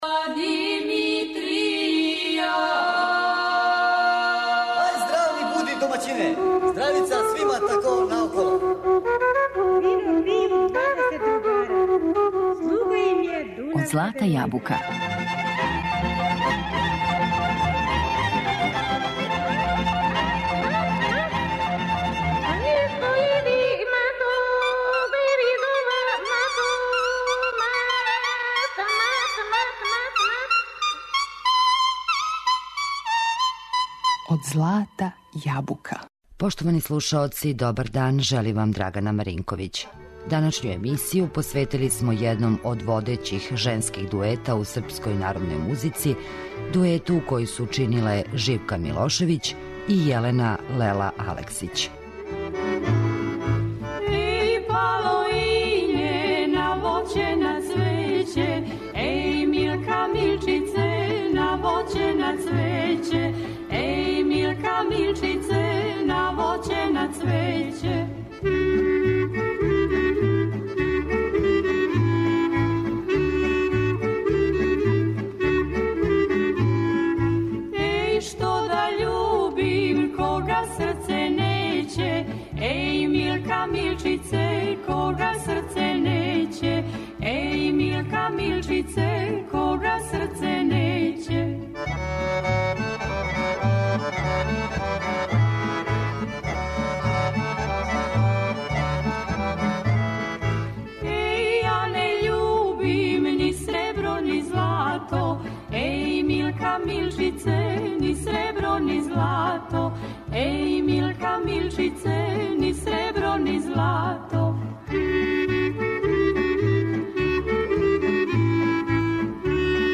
Две емисије "Од злата јабука" посветили смо једном од водећих женских дуета у српској народној музици
Овај дует настао је 1959. године и оствариле су велики броја трајних снимака са многим оркестрима у Радио Београду.